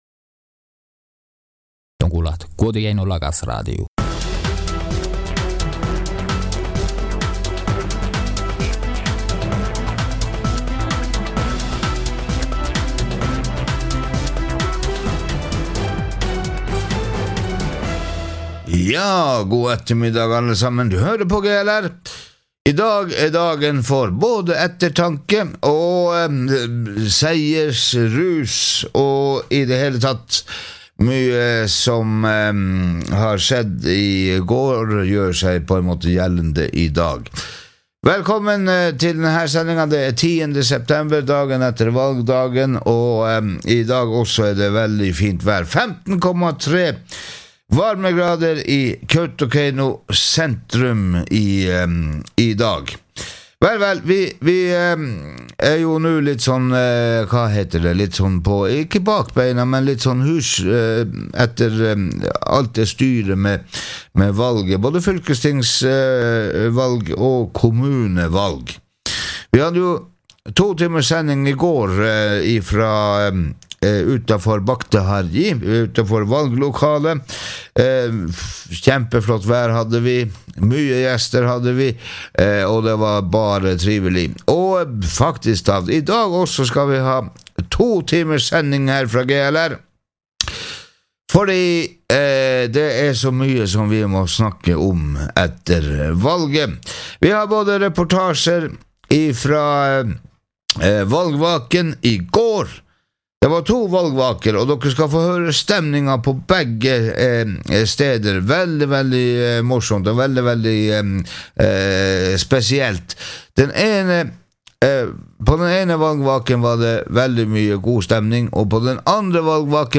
Dagen derpå: Guovdageainnu Lagasradio gir de beste analysene og vurderingene fra valgnatta, samt kommentarene fra stemmetellinga - i en to timer lang ekstrasending.